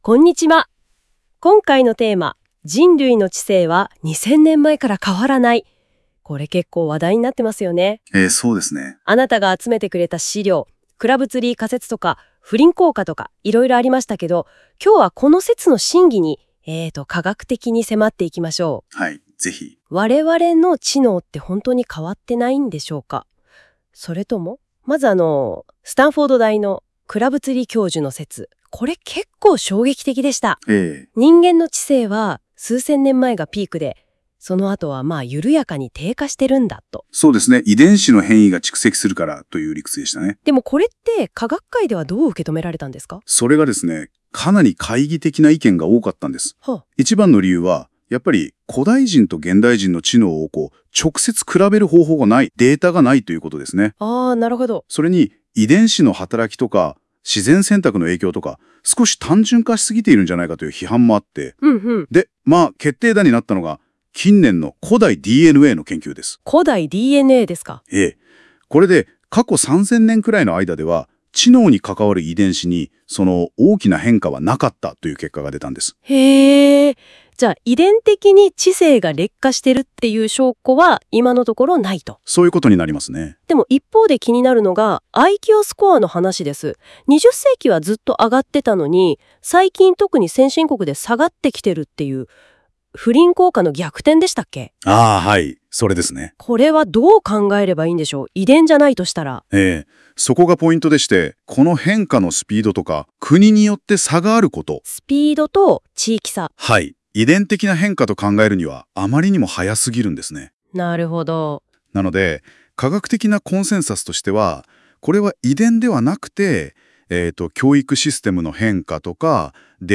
🎧 この記事の解説音声
忙しい方のために、この記事をポッドキャスト形式で解説しています。
※ この音声は Google NotebookLM を使用して記事内容から生成されています。